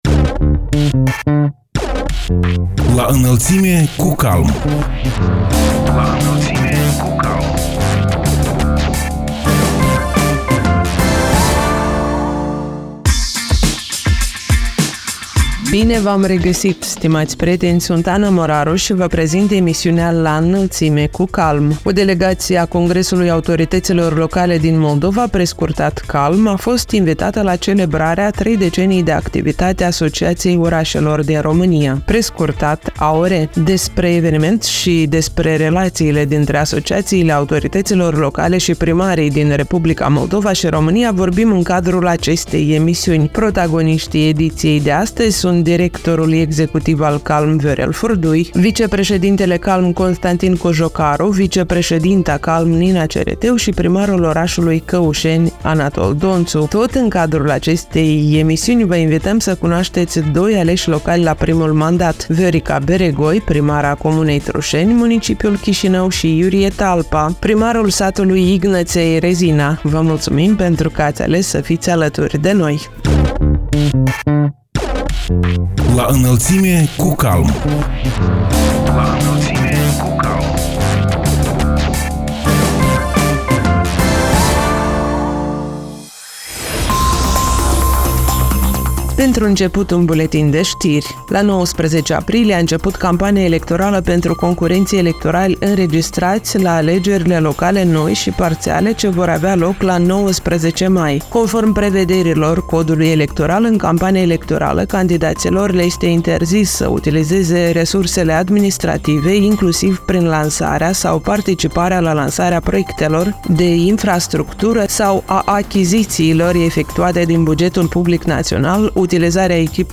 Tot în cadrul acestei emisiuni vă invităm să cunoașteți doi aleși locali la primul mandat: Viorica Beregoi, primara comunei Trușeni, municipiul Chișinău și Iurie Talpa, primarul satului Ignăței, Rezina.